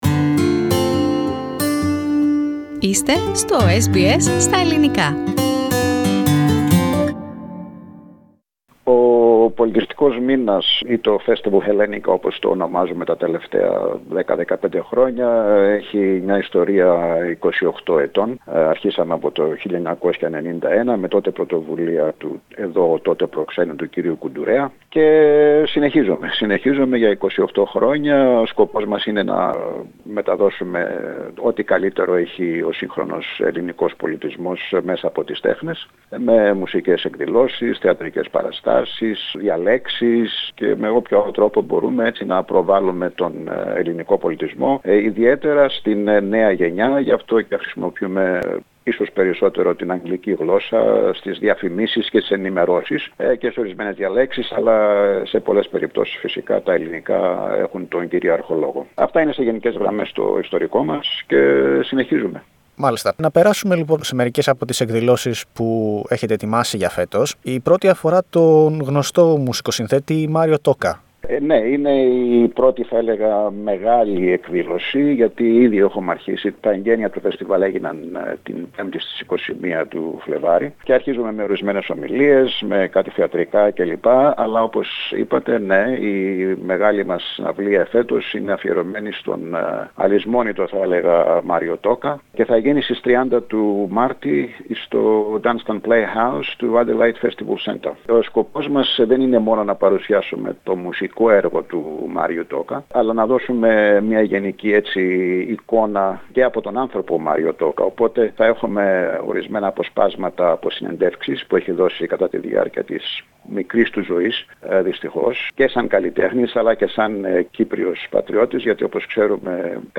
Press Play on the image to hear the interview in Greek.